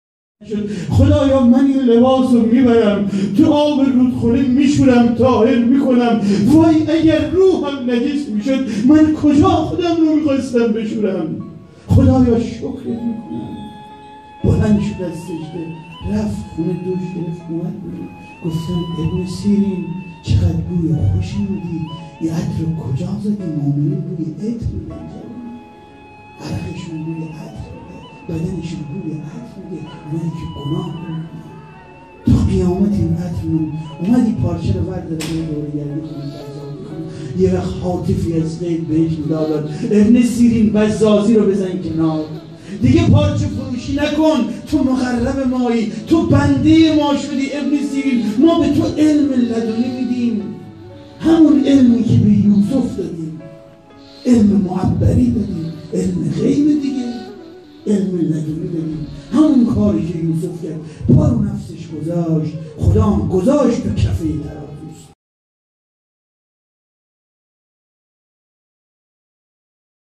سخنرانی | داستان ابن سیرین و علم تعبیر خواب
سخنرانی درباره داستان ابن سیرین | هیأت شهدای گمنام شهرستان صومعه سرا